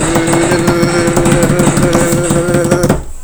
Instant meme sound effect perfect for videos, streams, and sharing with friends.